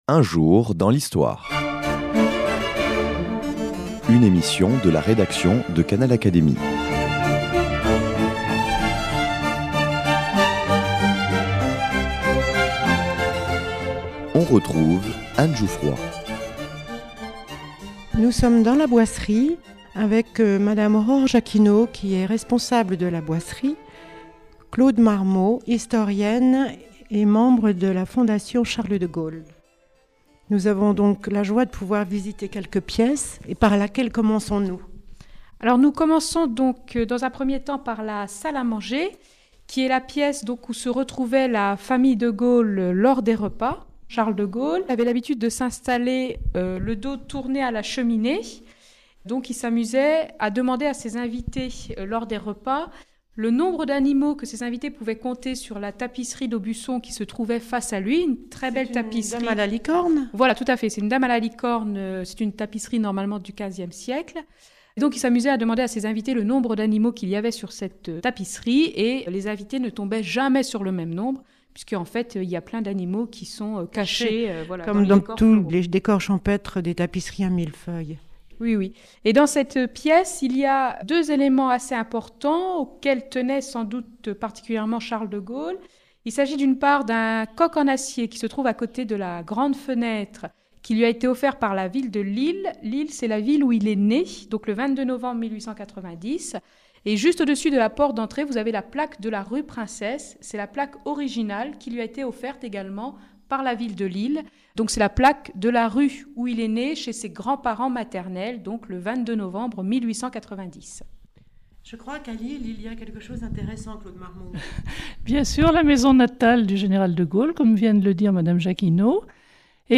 s’est rendue sur place pour Canal Académie